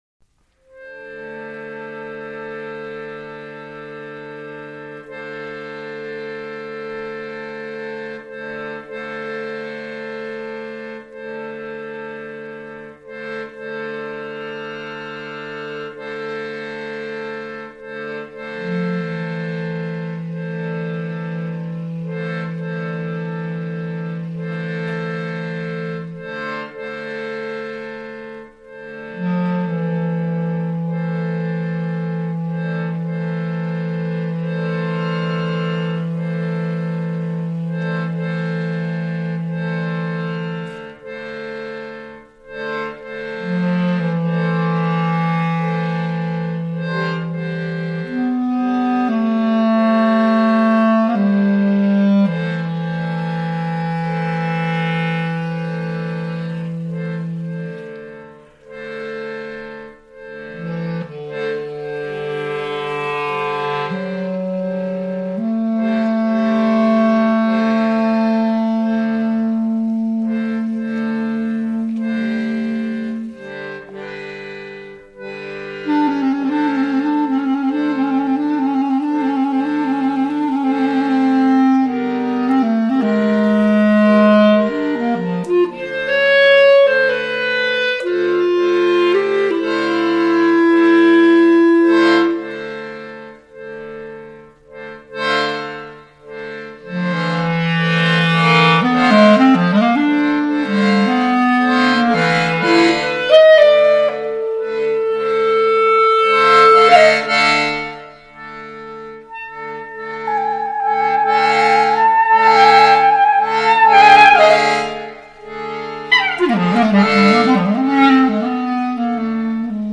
mal ernst, mal witzig, mal komisch oder mal melancholisch.
accordion, clarinet, voice